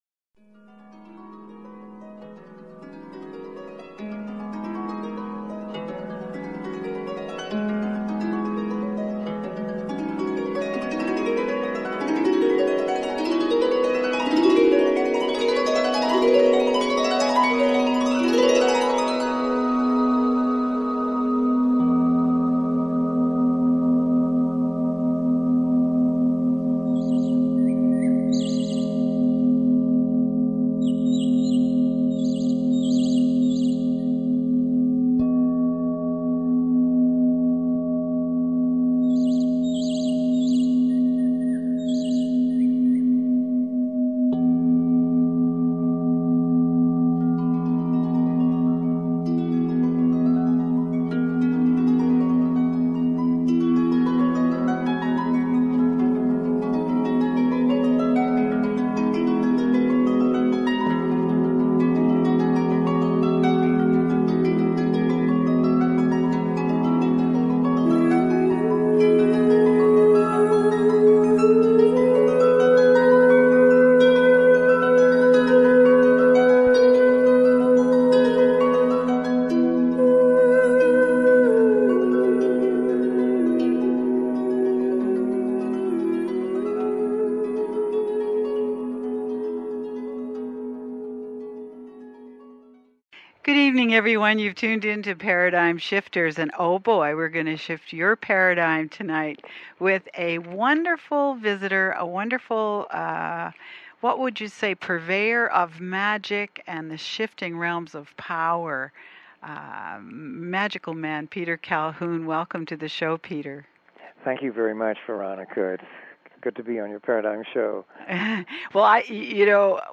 Talk Show Episode, Audio Podcast
This is an older interview but a lovely and encouraging - especially as we approach more dire climate circumstances.